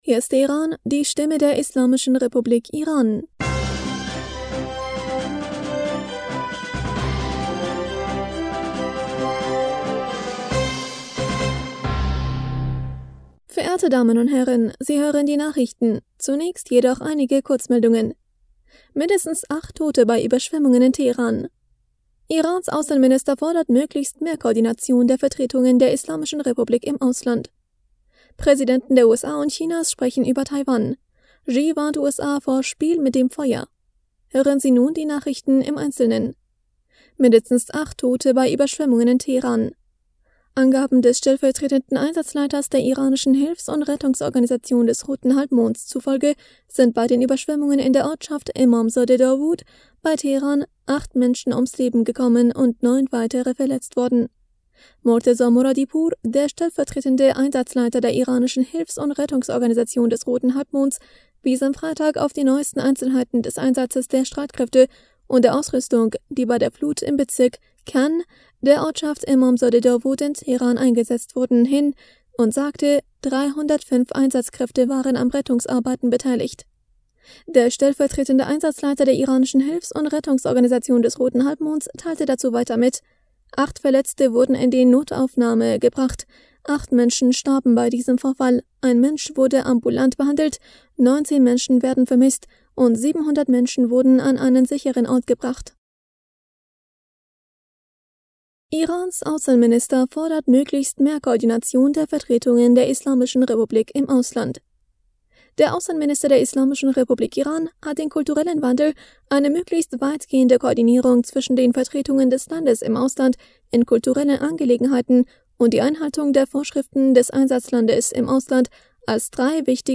Nachrichten vom 29. Juli 2022